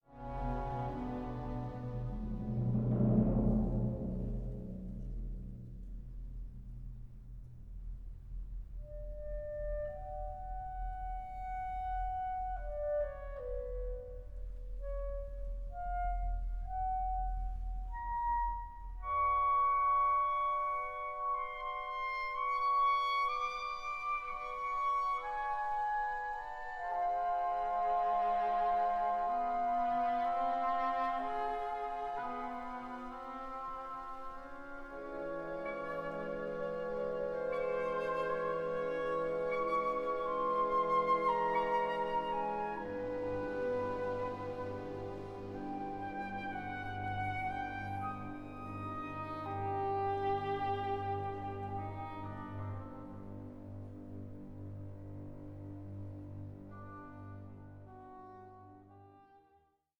tone poem
it’s a bold, optimistic and passionate work